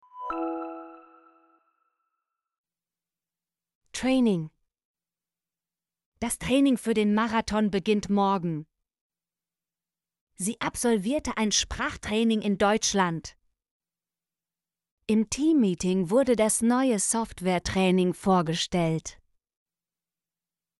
training - Example Sentences & Pronunciation, German Frequency List